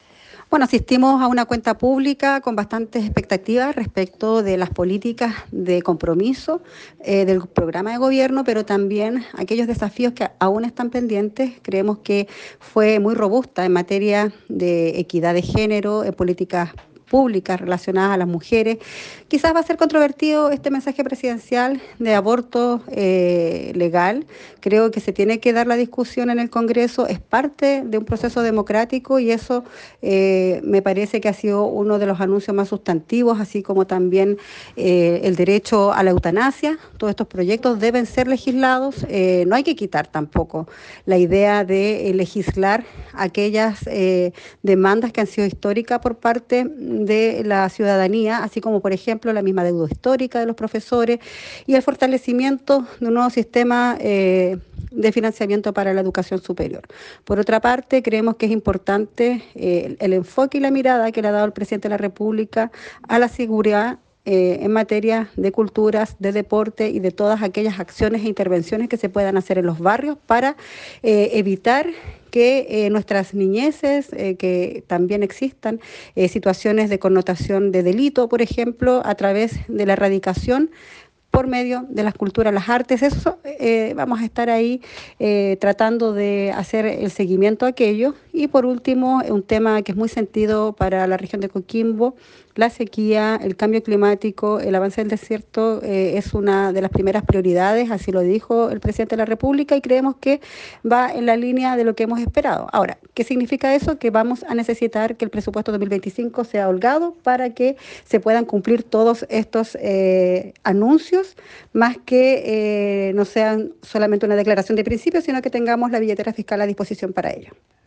Diputada Nathalie Castillo y Cuenta Pública 2024: